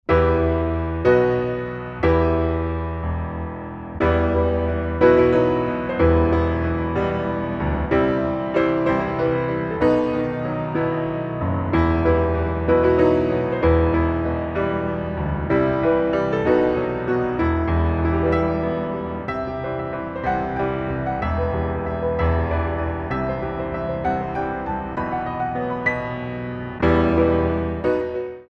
Grand Allegro
3/4 - 128 with repeat